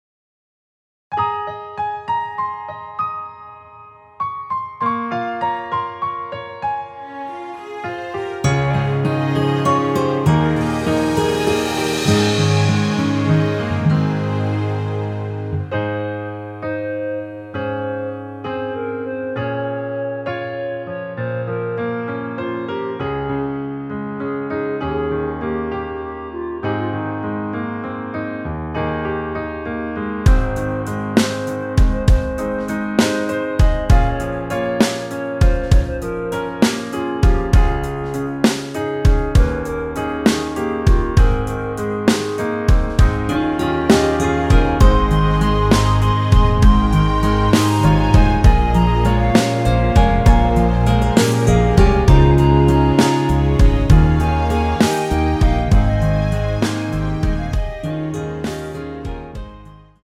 원키 멜로디 포함된 짧은 편곡 MR입니다.
Ab
앞부분30초, 뒷부분30초씩 편집해서 올려 드리고 있습니다.
중간에 음이 끈어지고 다시 나오는 이유는